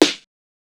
SNARE CRISP 2.wav